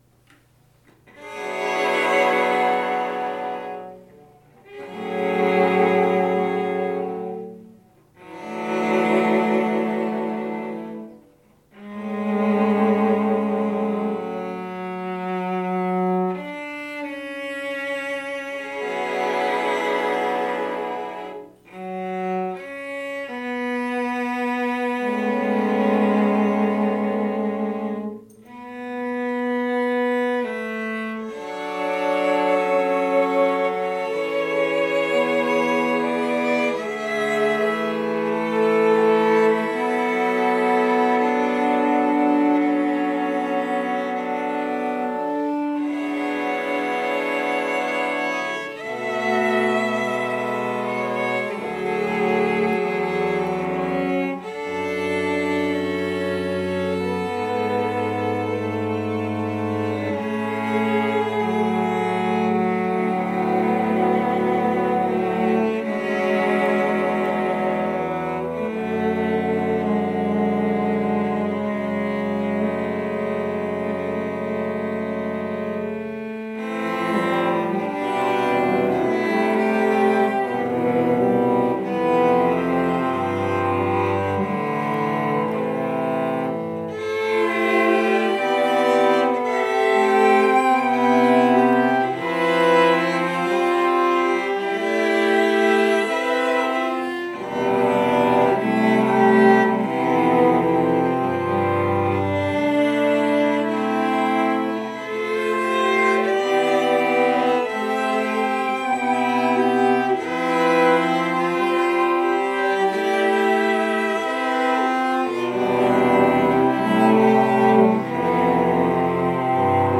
Through a YouTube video I found this very interesting cello quartet by Andrea Casarrubios.
The music is slow.
It has very close harmonies that require careful intonation. And it uses the full range of the instrument.
So I recorded the parts, making a version by myself: